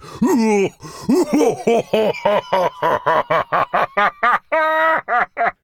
Sounds / Enemys / Giant / G_laugh2.ogg
G_laugh2.ogg